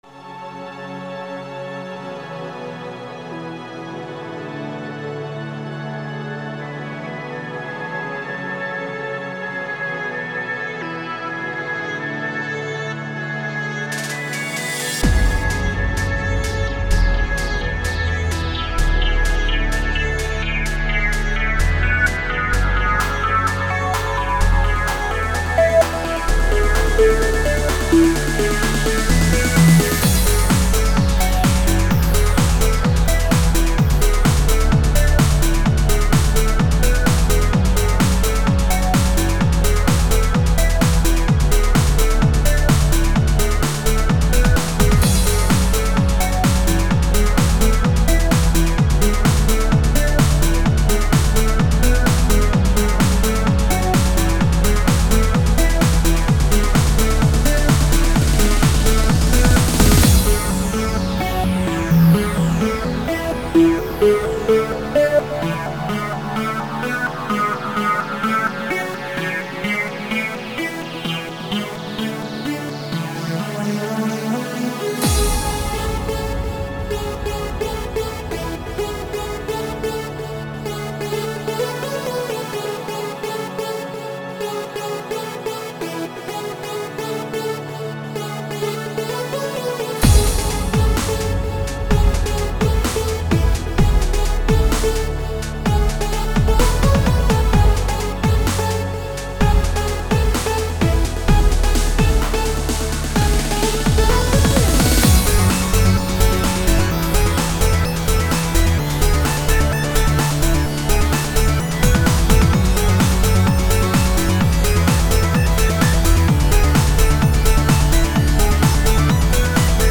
Music / Trance
trance edm music flstudio fruityloopsstudio fruity loops studio custom original song relaxing track dance techno